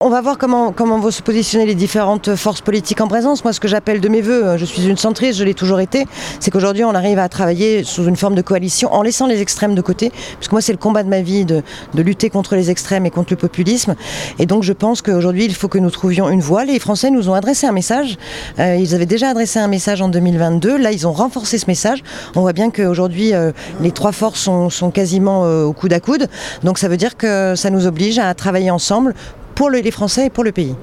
On écoute Marina Ferrari.